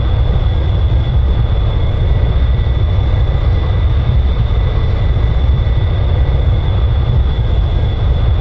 f16-engine-int.wav